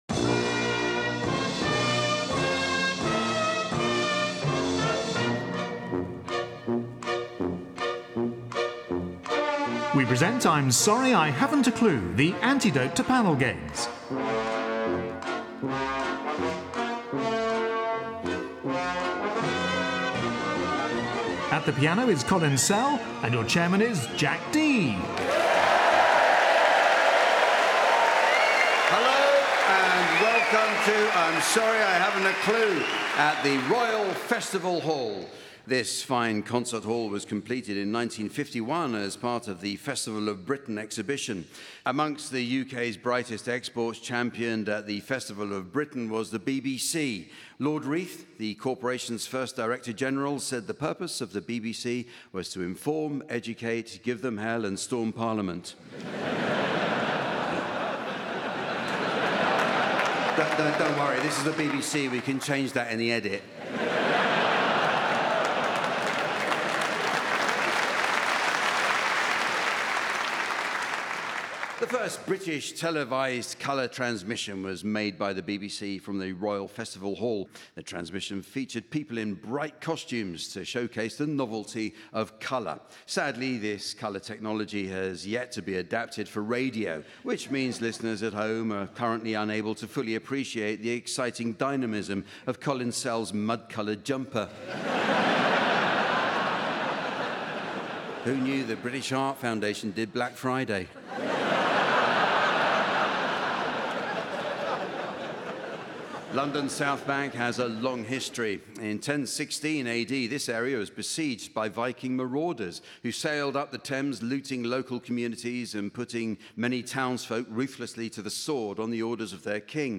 The godfather of all panel shows comes to us from London's Royal Festival Hall.
On the panel are Joe Lycett, Pippa Evans, Richard Coles and Tony Hawks with Jack Dee in the umpire's chair. Regular listeners will know to expect inspired nonsense, pointless revelry and Colin Sell at the piano.